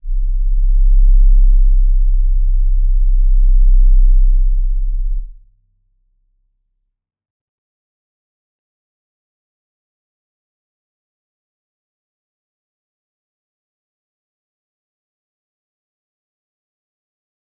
G_Crystal-C1-mf.wav